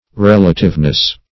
Search Result for " relativeness" : The Collaborative International Dictionary of English v.0.48: Relativeness \Rel"a*tive*ness\, n. The state of being relative, or having relation; relativity.